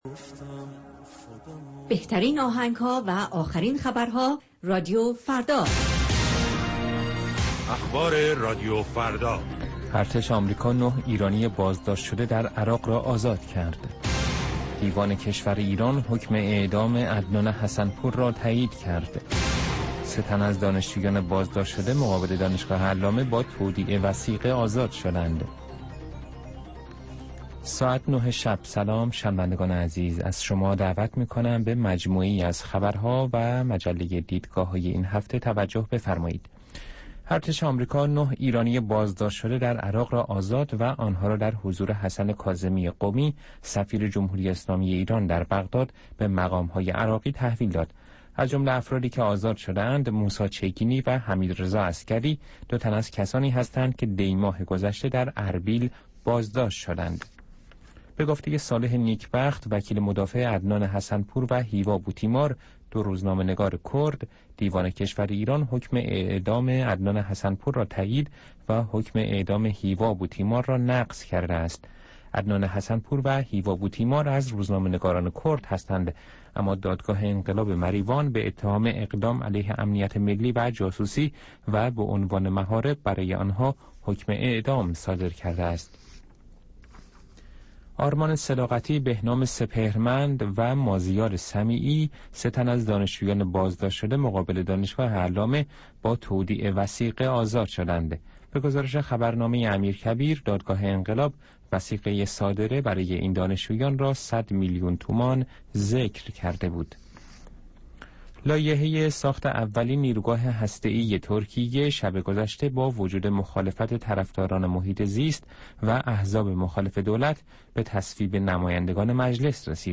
نیم ساعت با تازه ترین خبرها، گزارشهای دست اول در باره آخرین تحولات جهان و ایران از گزارشگران رادیوفردا در چهارگوشه جهان، گفتگوهای اختصاصی با چهره های خبرساز و کارشناسان، و مطالب شنیدنی از دنیای سیاست، اقتصاد، فرهنگ، دانش و ورزش.